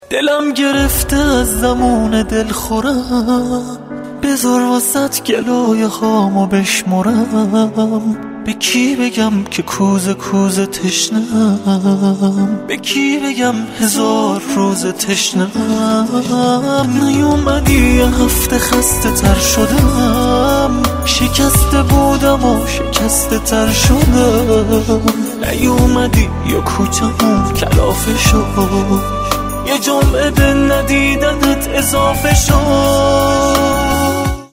(با کلام)
با ملودی عاشقانه و محزون